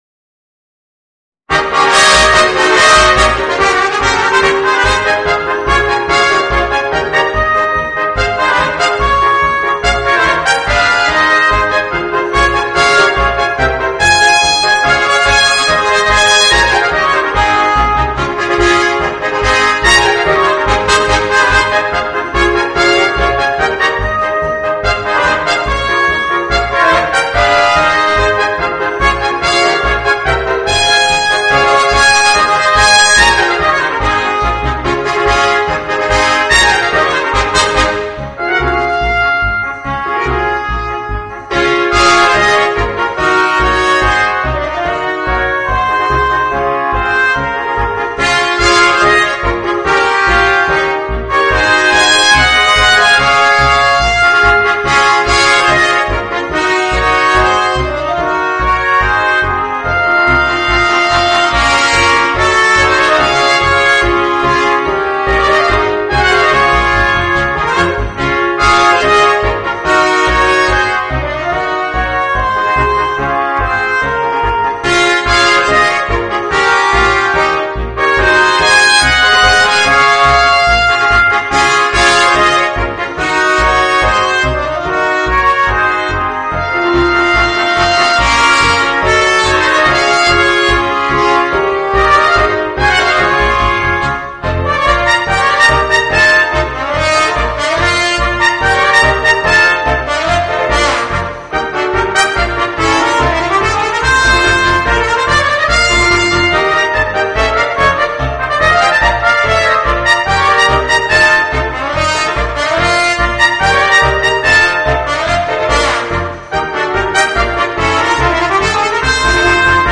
Voicing: 5 Trumpets and Piano